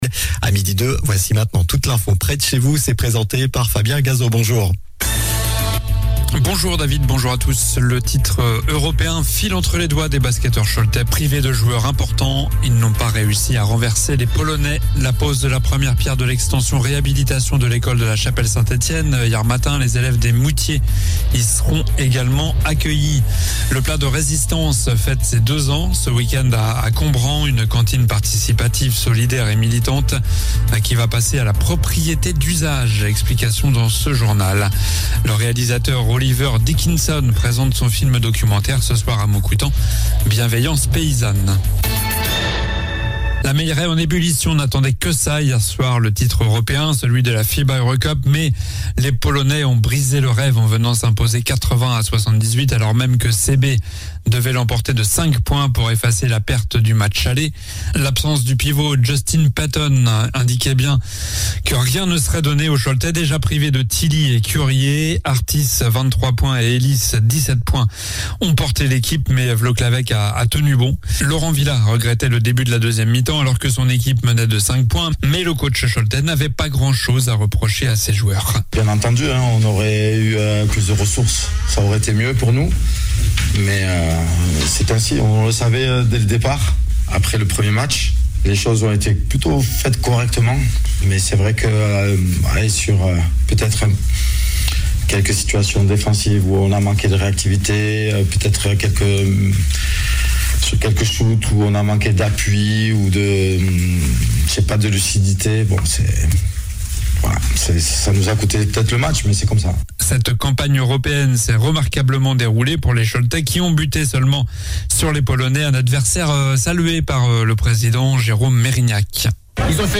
Journal du jeudi 27 avril (midi)